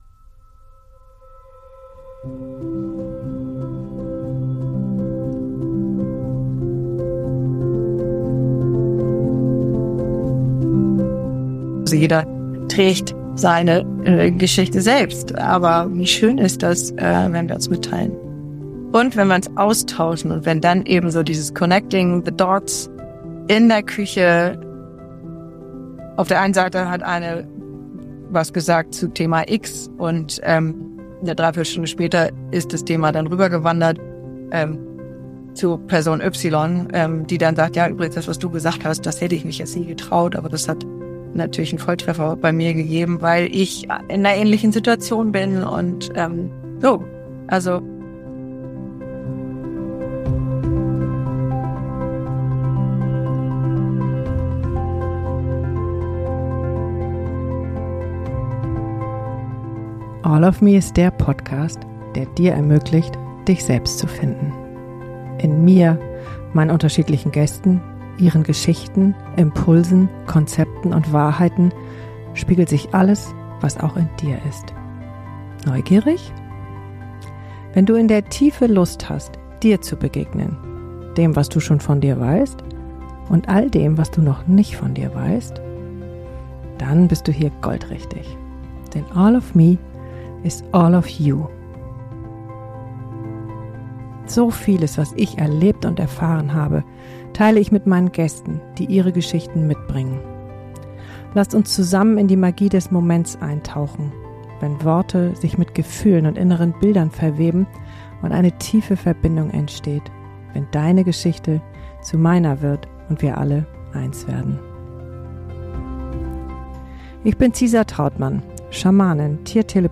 Beschreibung vor 4 Monaten Zum November-Abschluss eine Solofolge.